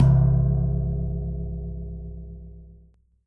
snd_ui_gold1.wav